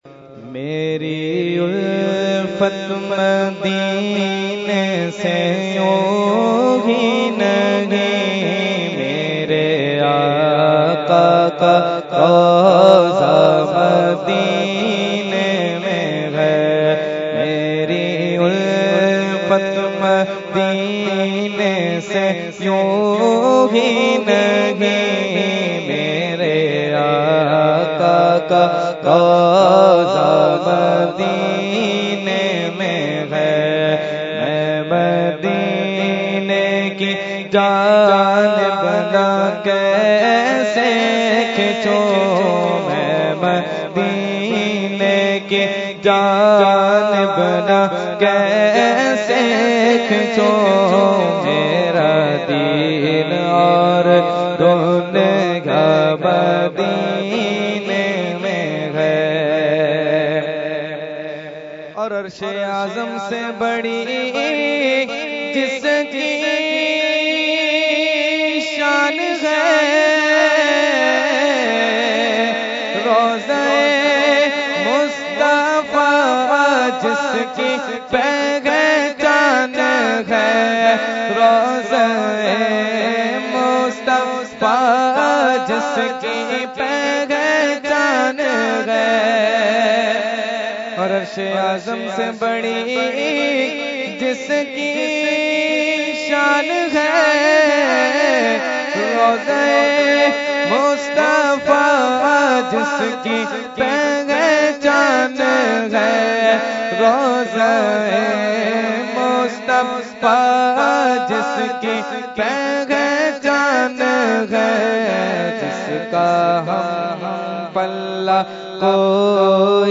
Category : Naat | Language : UrduEvent : Urs Qutbe Rabbani 2018